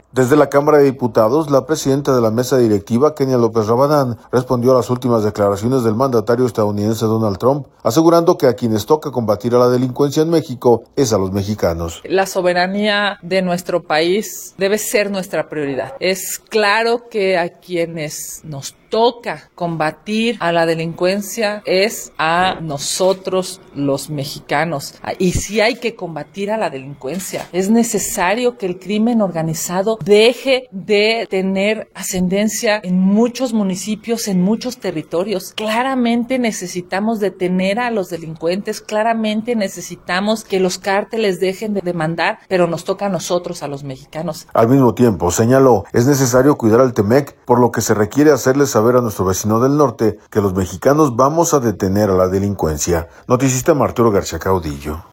Desde la Cámara de Diputados, la presidenta de la Mesa Directiva, Kenia López Rabadán, respondió a las últimas declaraciones del mandatario estadounidense Donald Trump, asegurando que a quienes toca combatir a la delincuencia en México es a los mexicanos.